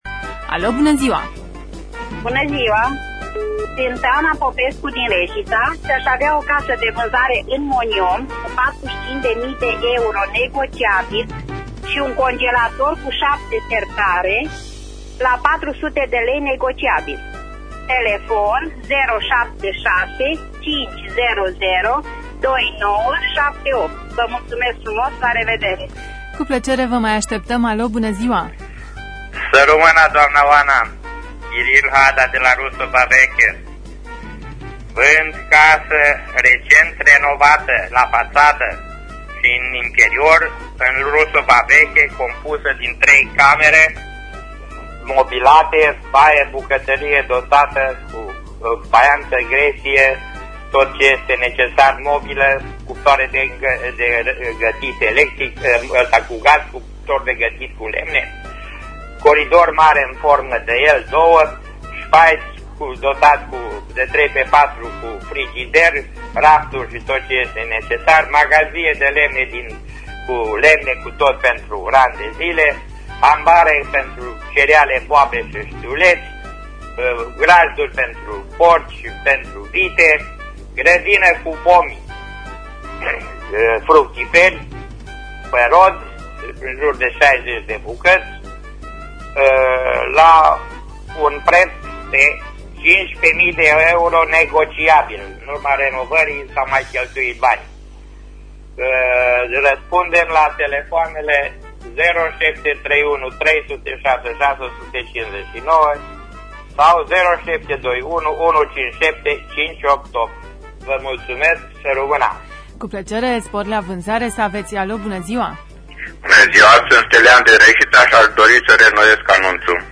Înregistrarea emisiunii „Târgul de bunuri” de vineri, 17.07.2015, difuzată la Radio România Reşiţa.